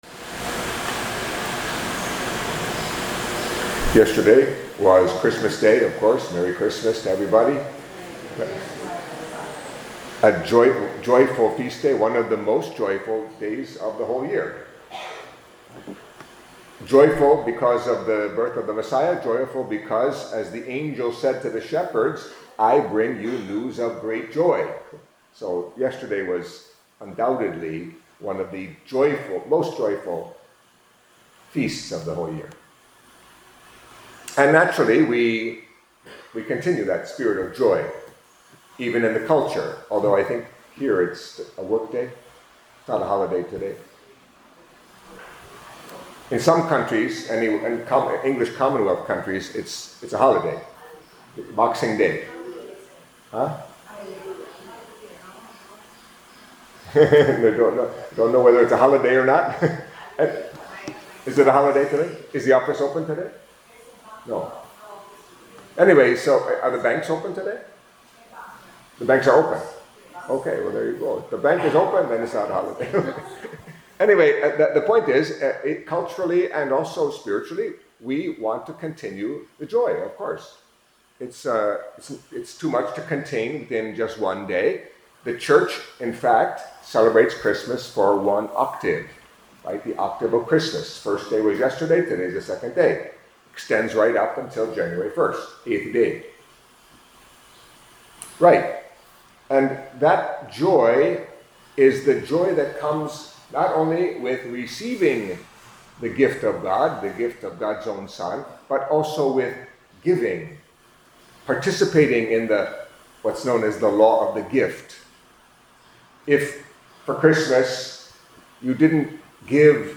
Catholic Mass homily for Feast of Saint Stephen